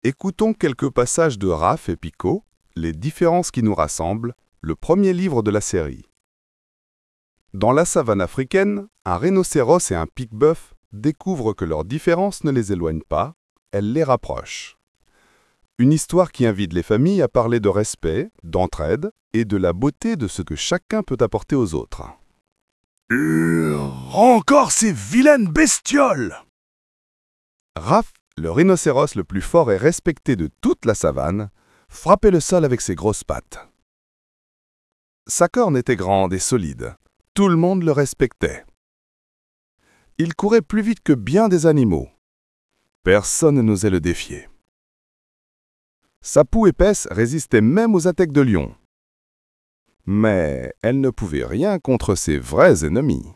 Lectures (audio)